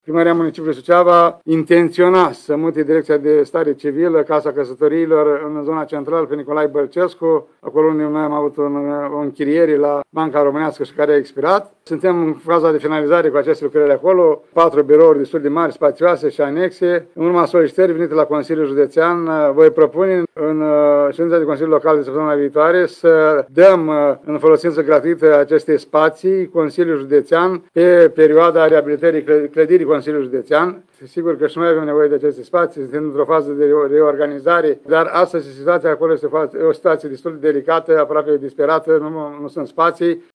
Primarul ION LUNGU a declarat astăzi că acest transfer de folosință este “un gest de solidaritate pentru administrația județeană”, a cărei activitate a fost afectată de incendiul de la Palatul Administrativ.